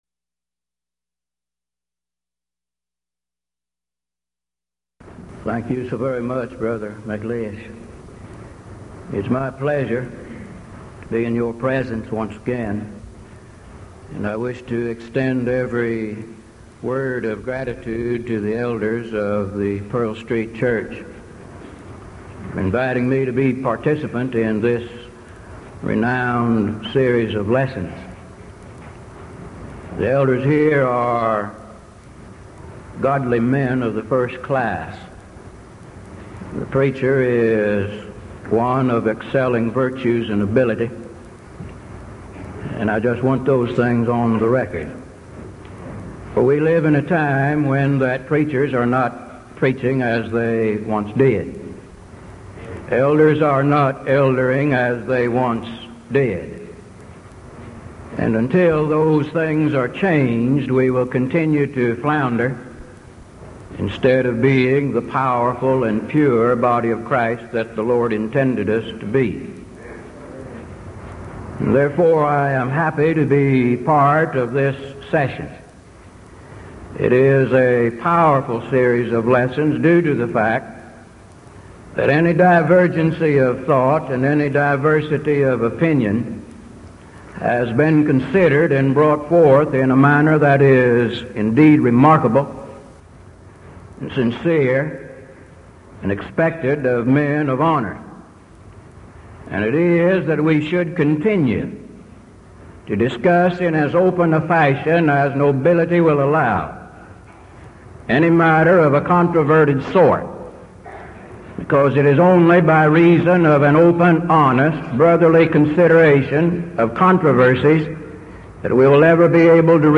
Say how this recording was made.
Event: 1982 Denton Lectures Theme/Title: Studies in 1 Corinthians